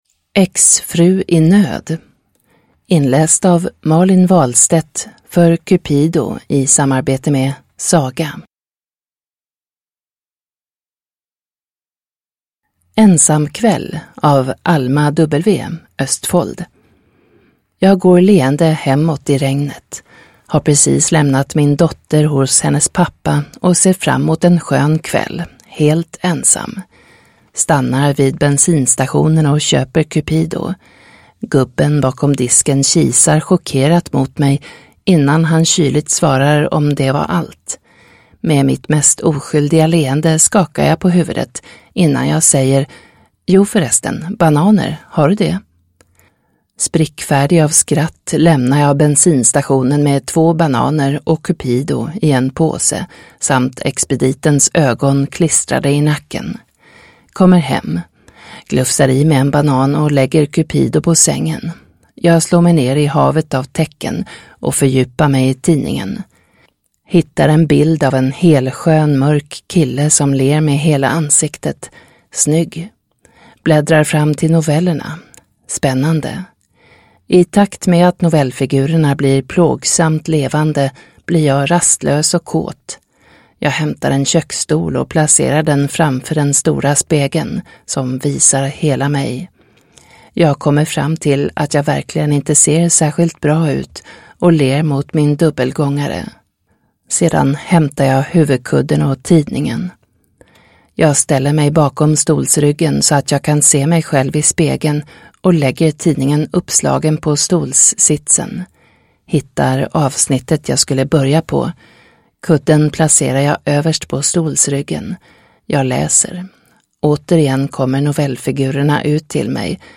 Exfru i nöd (ljudbok) av Cupido